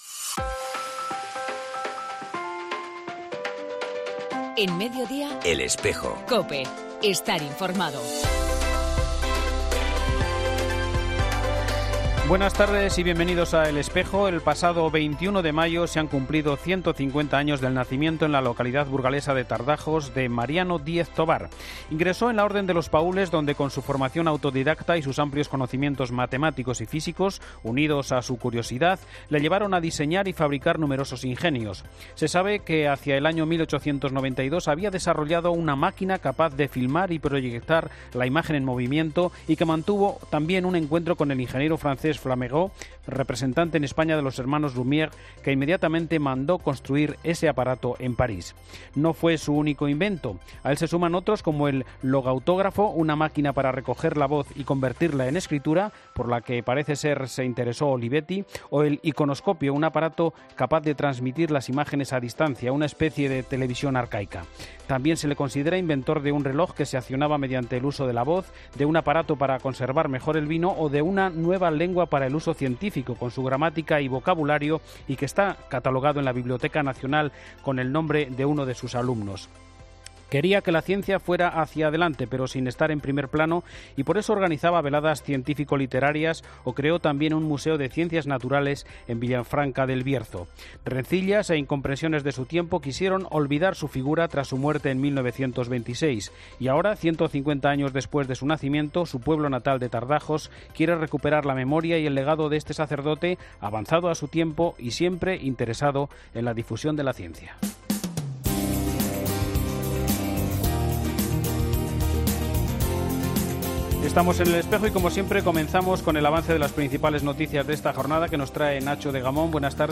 AUDIO: En el programa de hoy entrevistamos a Santiago Gómez Sierra, obispo auxiliar de Sevilla y Responsable del Departamento de Santuarios...